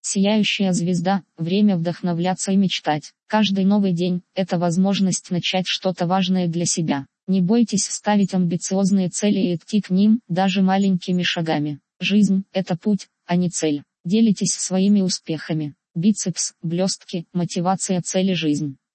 Озвучка текста.